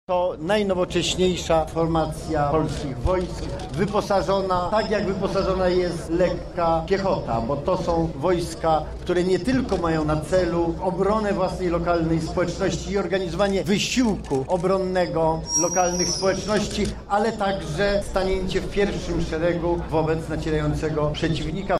Słowa wojskowej przysięgi wypowiedziane zostały na Placu zamkowym w Lublinie w obecności Ministra obrony Narodowej, dowódców wojskowych oraz władz samorządowych.
O obronie terytorialnej mówi Antonii Macierewicz, szef MON.